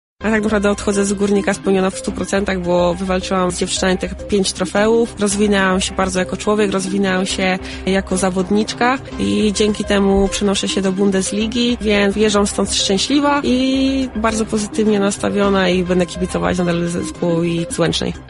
Cała rozmowa dostępna jest tutaj: